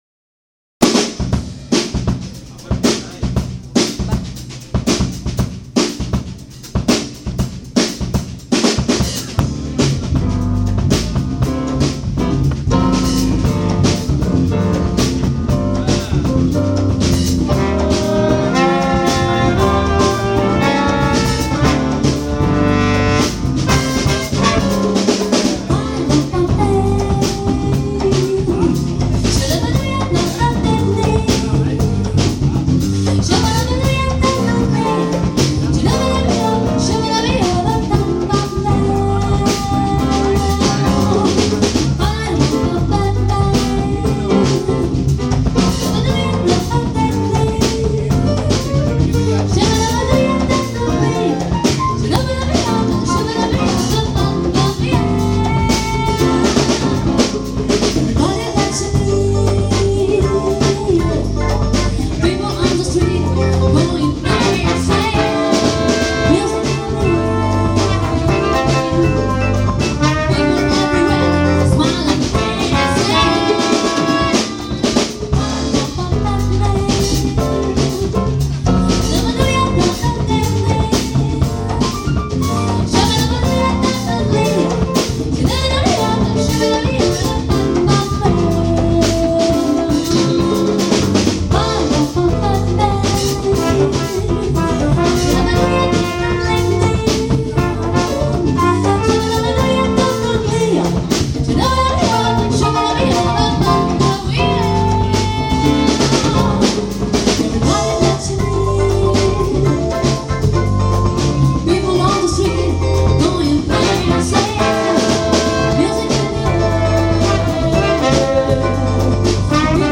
LIVE IN ROVIGO